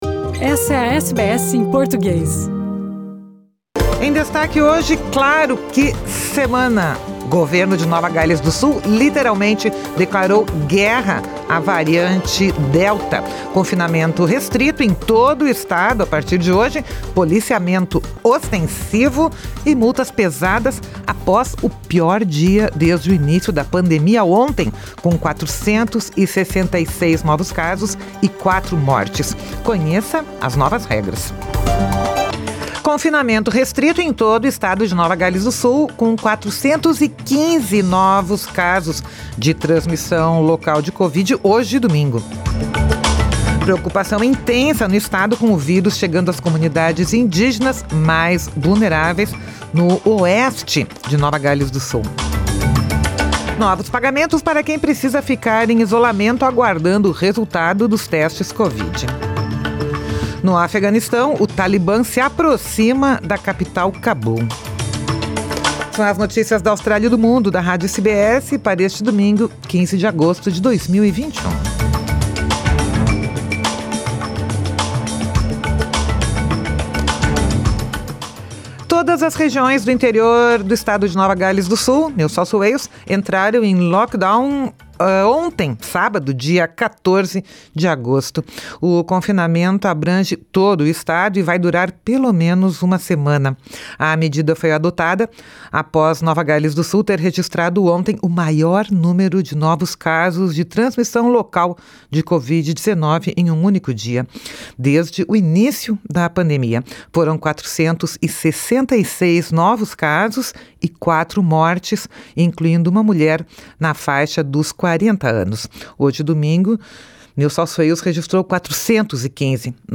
São as notícias da Austrália e do Mundo da Rádio SBS para este domingo, 15 de agosto de 2021 Confinamento restrito em todo o estado de Nova Gales do sul, com 415 novos casos de transmissão local de COVID-19 neste domingo.